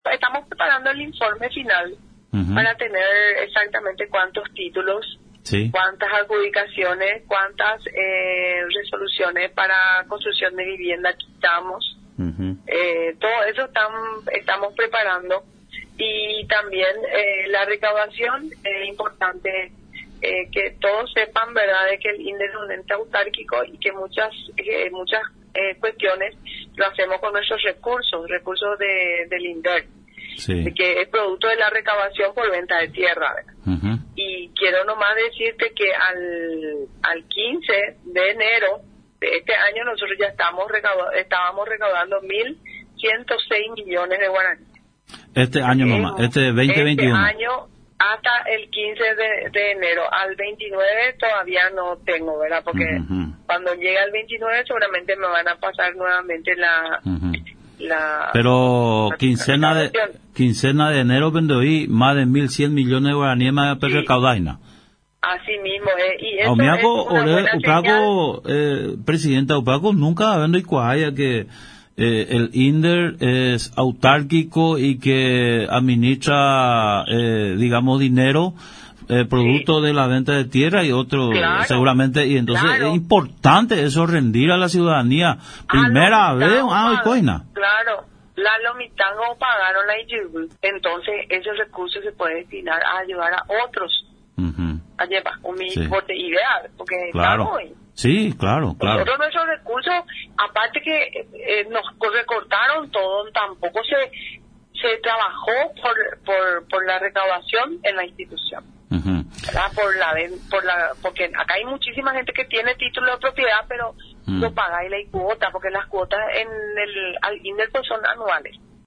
La Abg. Gail González, presidenta del Instituto Nacional de Desarrollo Rural y de la Tierra (INDERT), en conversación con Radio Nacional San Pedro, comentó de las principales acciones que vienen desarrollando al frente de la institución mediante el trabajo articulado e interinstitucional, logrando avances importantes en materia de regularización de tierras en el año 2020, que se agilizaron durante los primeros meses de gestión de la Mg. Abg. Gail González al frente del ente rural.